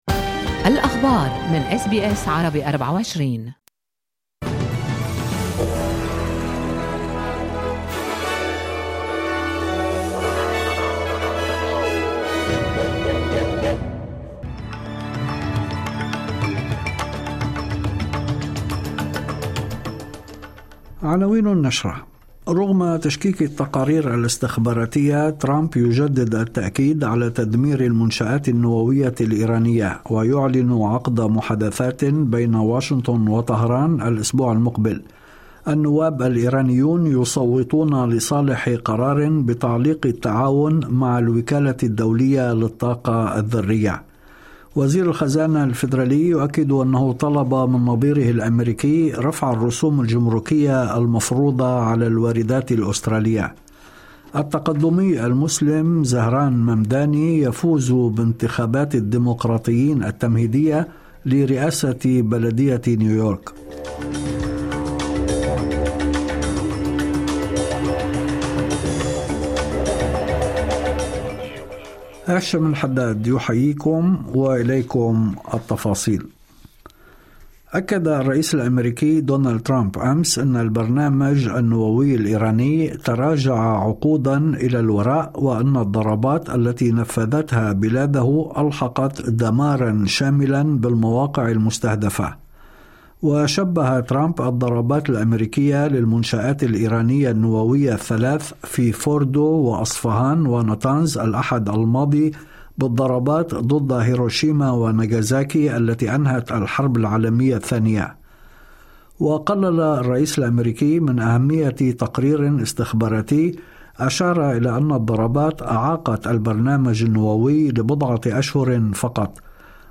نشرة أخبار المساء 26/6/2025